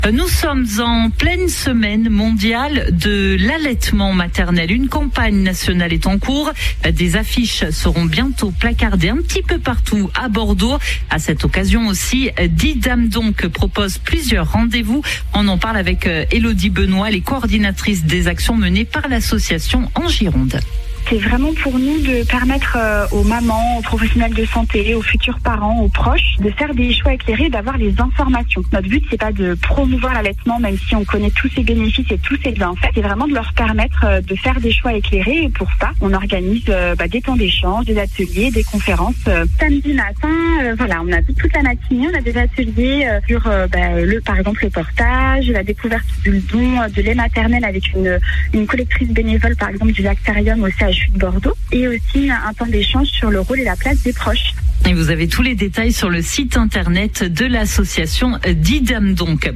Interview DisDameDonc SMAM 2025